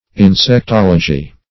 Search Result for " insectology" : The Collaborative International Dictionary of English v.0.48: Insectology \In`sec*tol"o*gy\, n. [Insect + -logy: cf. F. insectologie.]